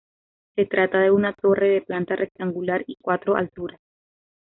Read more rectangular Hyphenated as rec‧tan‧gu‧lar Pronounced as (IPA) /reɡtanɡuˈlaɾ/ Etymology From rectángulo + -ar.